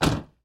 汽车门的开启和关闭03
描述：车门开启和关闭的声音。
标签： 地一声 踩住 现场 记录 汽车 汽车
声道立体声